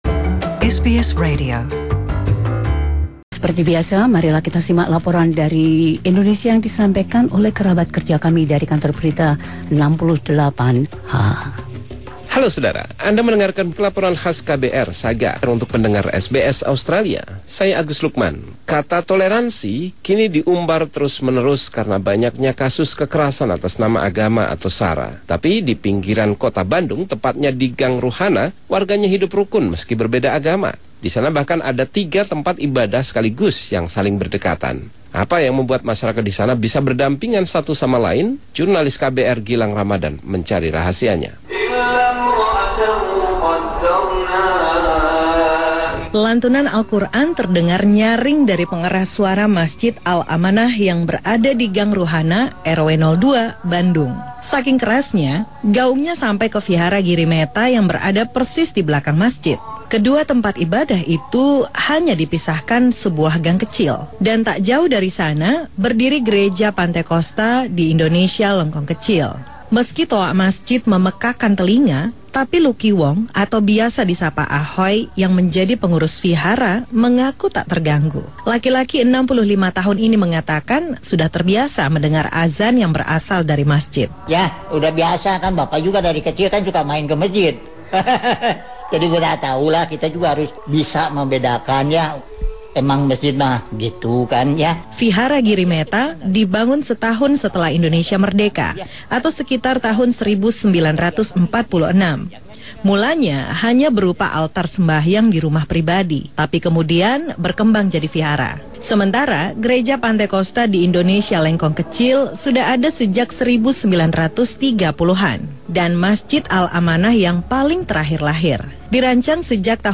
This special report from the team at KBR 68 H tells of the lane where residents actively encourage diversity and tolerance.